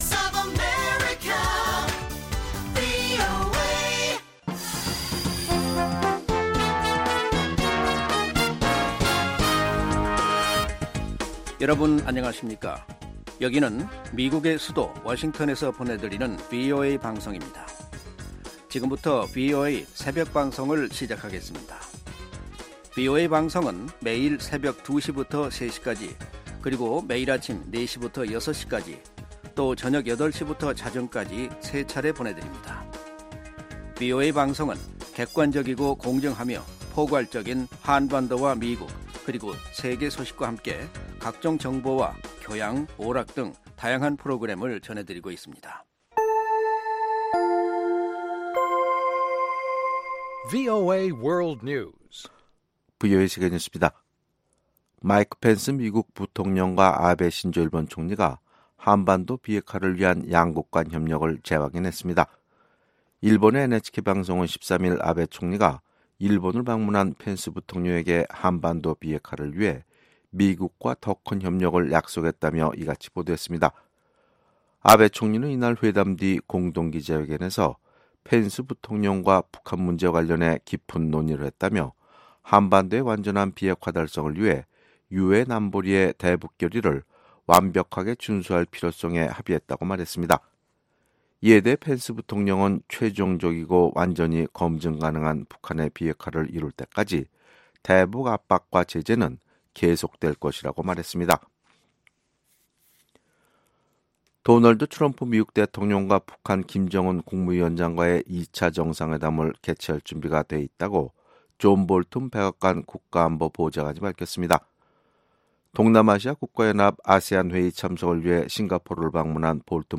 VOA 한국어 '출발 뉴스 쇼', 2018년 11월 14일방송입니다. 미 행정부 안에 북한과의 협상을 긍정적으로 묘사하는 트럼프 대통령의 말과는 다른 기류가 형성되고 있다는 전직 미 외교당국자들의 지적이 나왔습니다. 북한이 신고하지 않은 미사일 기지 13곳을 운영하고 있다고 미국 전략국제문제연구소(CSIS) 가 밝혔습니다.